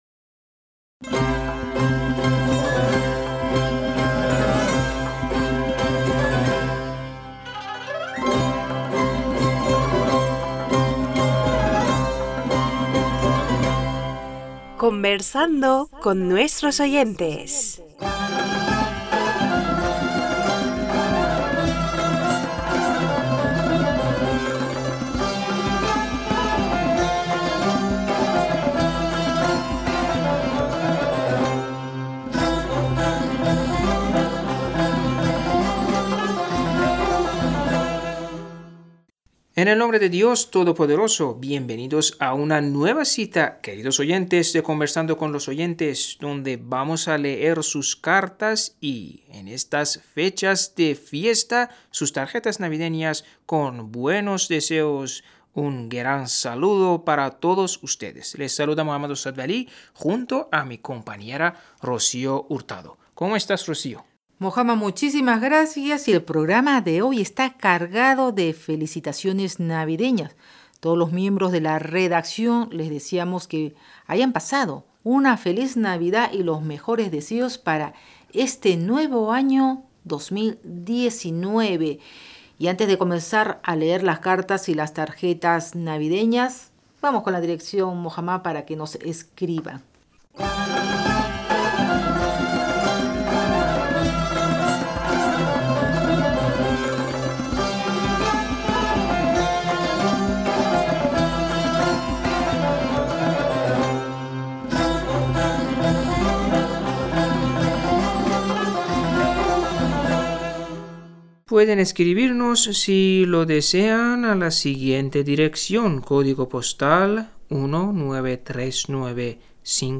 Pars Today-Entrevistas, leer cartas y correos de los oyentes de la Voz Exterior de la R.I.I. en español.